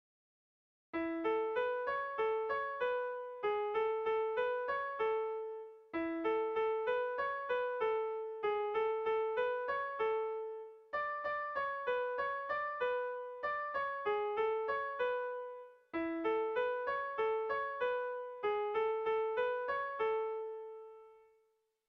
Tragikoa
Zortziko txikia (hg) / Lau puntuko txikia (ip)
A1A2BA1